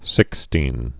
(sĭkstēn, -stīn)